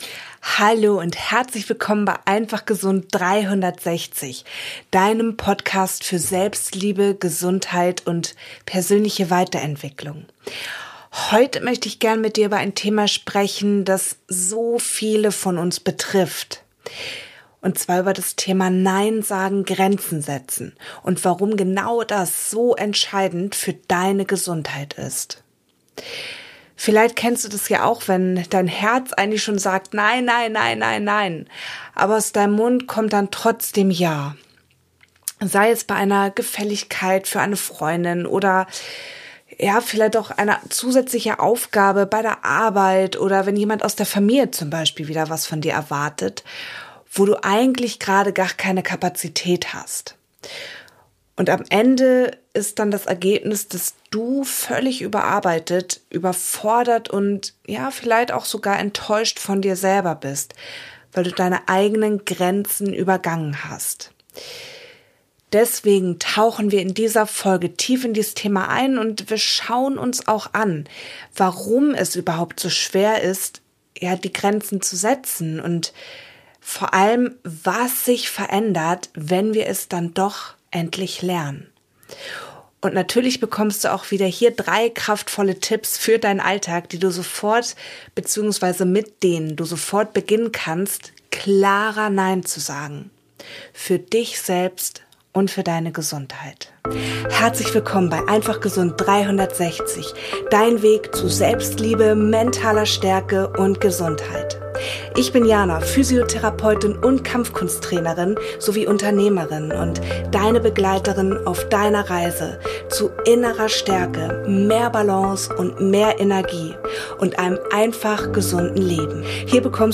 Audition Template: 24 Mono tracks routed to a Stereo Master. 44.1k, 24 bit, Stereo Master.